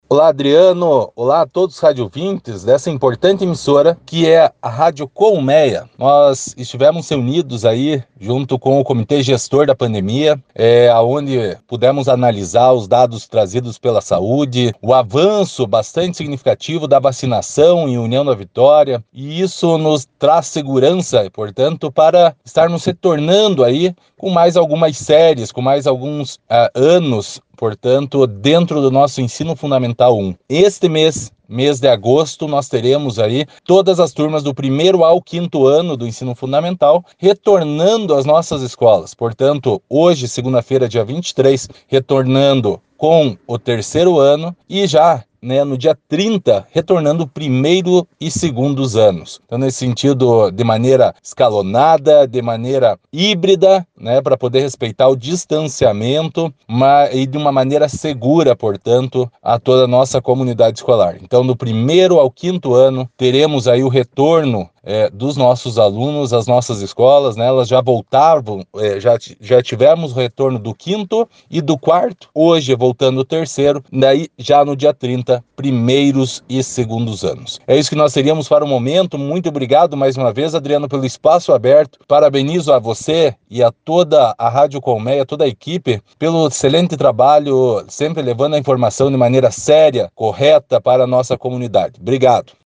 Acompanhe o áudio do secretário de Educação, Ricardo Brugnago.